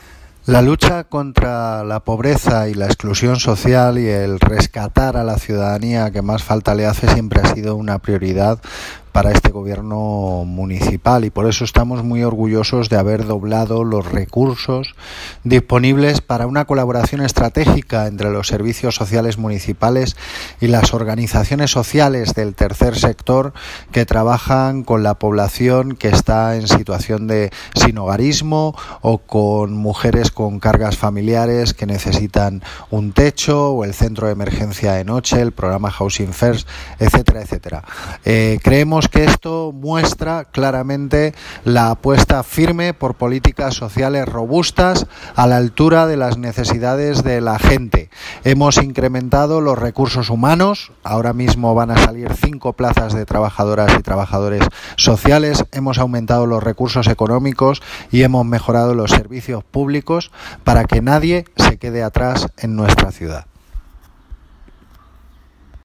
Audio - Gabriel Ortega (Concejal de Cultura, Bienestar Social y Vivienda) Sobre subvenciones servicios sociales